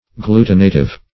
Search Result for " glutinative" : The Collaborative International Dictionary of English v.0.48: Glutinative \Glu"ti*na*tive\, a. [L. glutinativus: cf. F. glutinatif.]